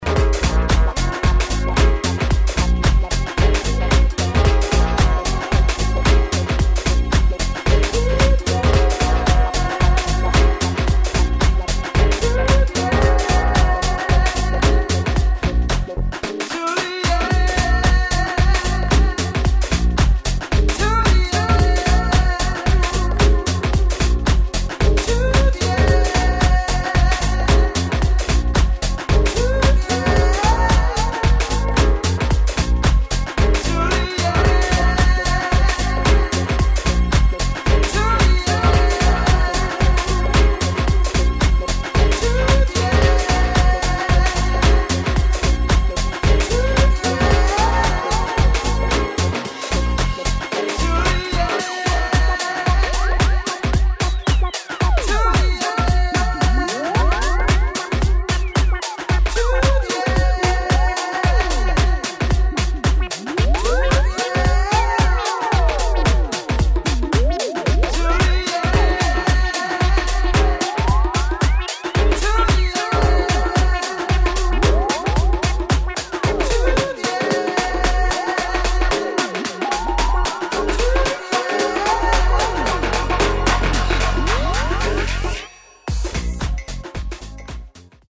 [ SOUL / FUNK / LATIN ]
Gospel Remix